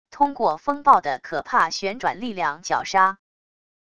通过风暴的可怕旋转力量绞杀wav音频